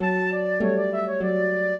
flute-harp
minuet11-5.wav